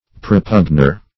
Search Result for " propugner" : The Collaborative International Dictionary of English v.0.48: Propugner \Pro*pugn"er\, n. A defender; a vindicator.